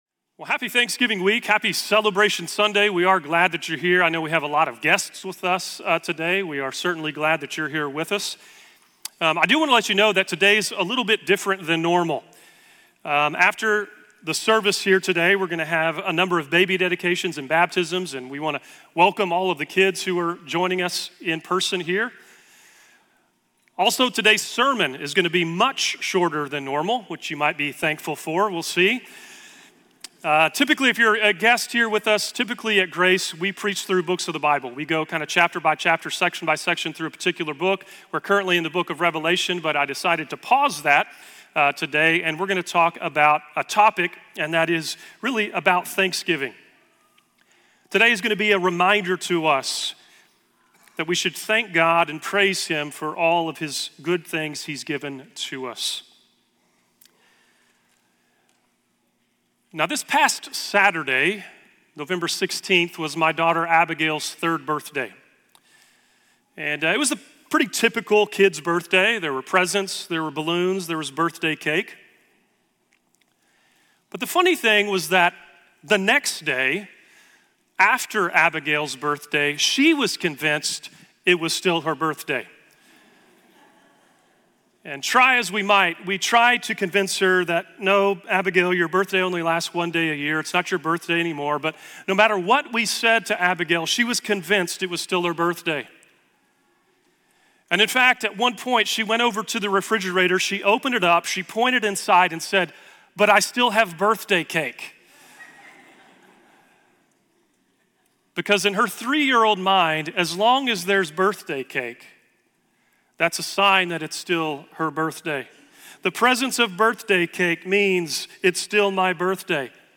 A Bite-Sized Sermon on Food | Genesis 3, Matthew 26, Isaiah 25 | 11.24.2024